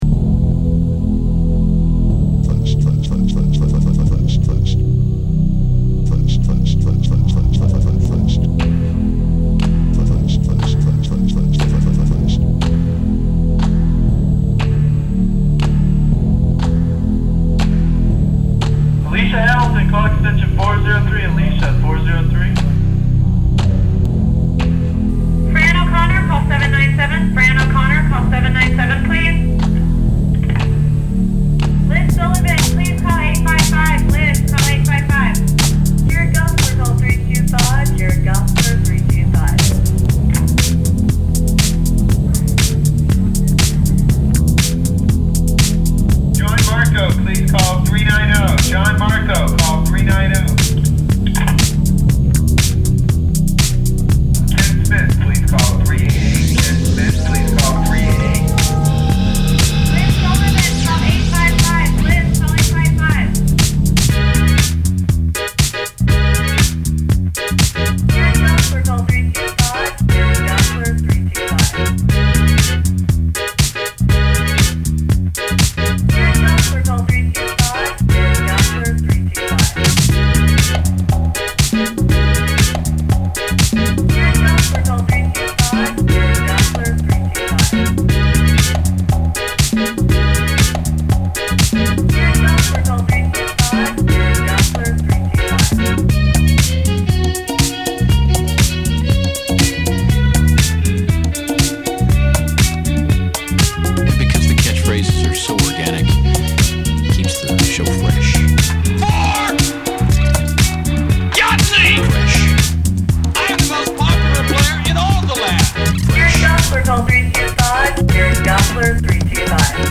All the music was built with a sampler and keyboard from the stuff people sent in - with some of the ads mxed in there too.
I'm still quite pleased with most of them but some are a little dated - I was going through a bit of a big beat phase.
There are Windows sounds all over it.
Including: lots of paging, some ESPN out-takes and demo sounds from a Coke 'audio logo' they never bought.